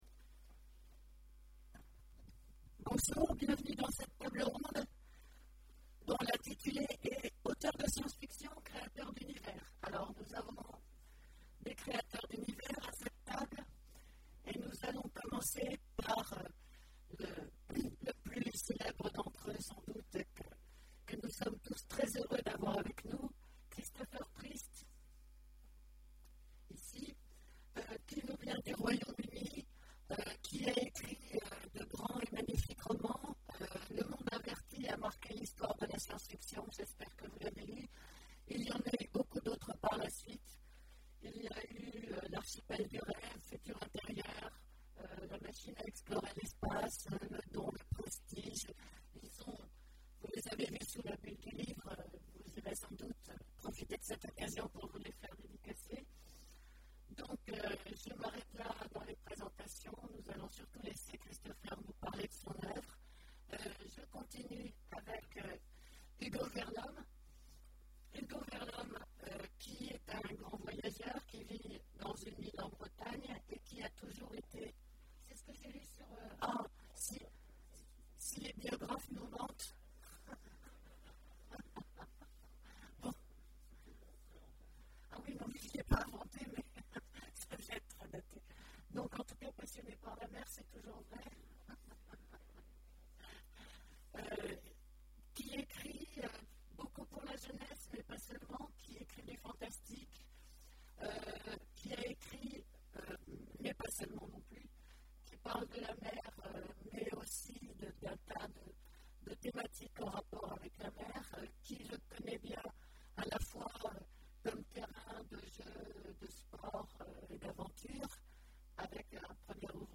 Imaginales 2014 : Conférence Auteurs de science-fiction...